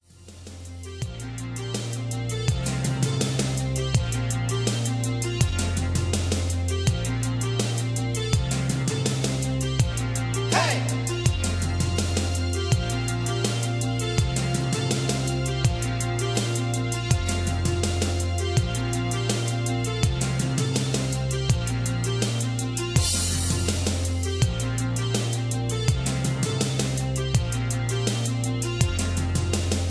Just Plain & Simply "GREAT MUSIC" (No Lyrics).